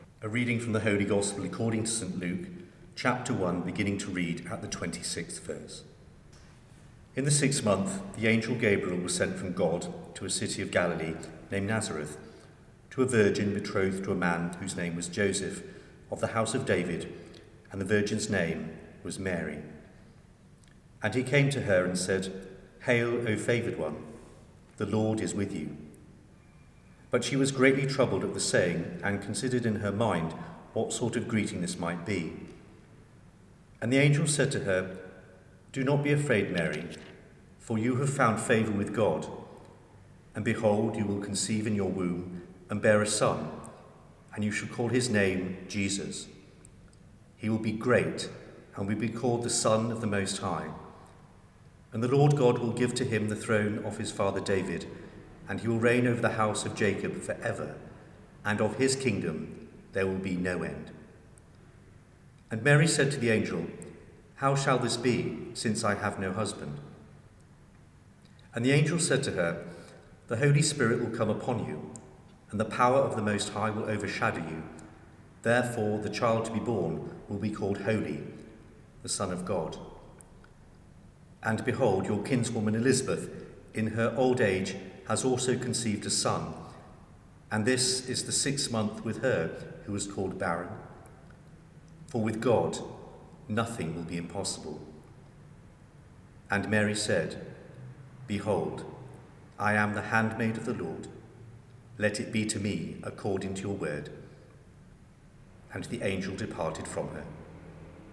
The season of Advent has begun and we have recorded the key readings that relate to the birth of Jesus Christ, our Saviour.